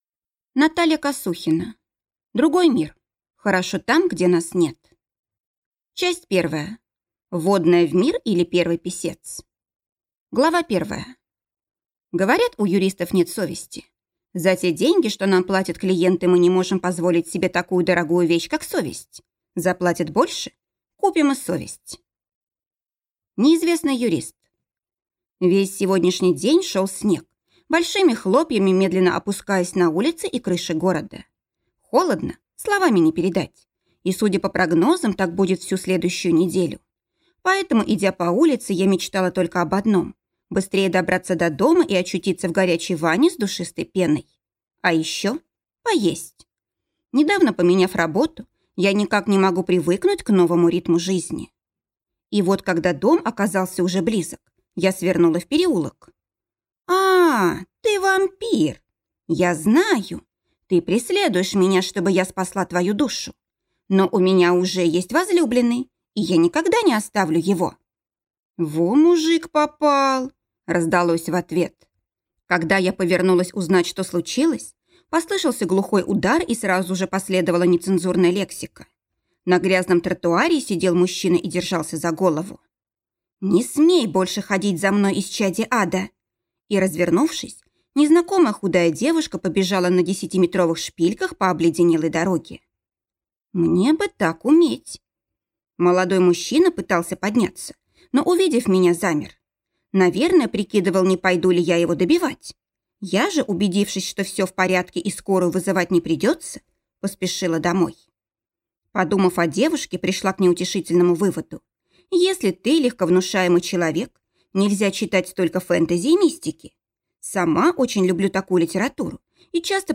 Аудиокнига Другой мир. Хорошо там, где нас нет | Библиотека аудиокниг